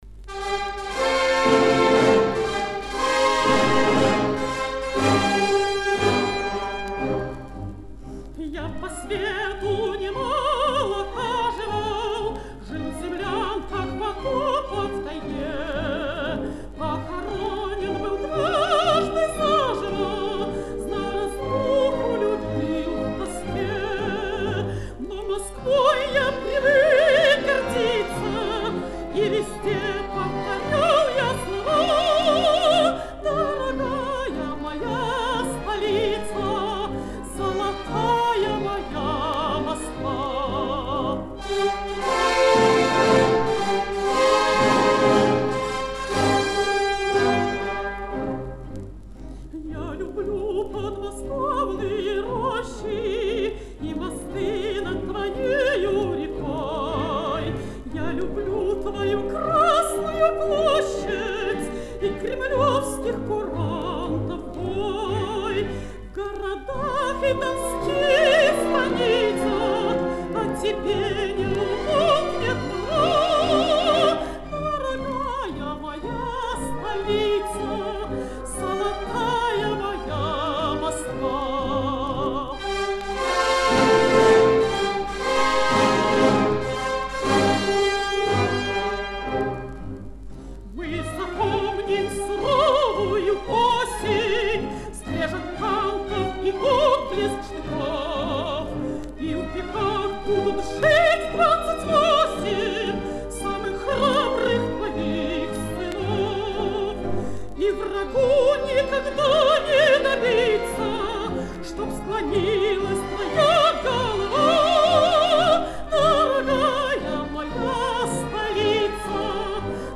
Другой вариант исполнения песни певицей.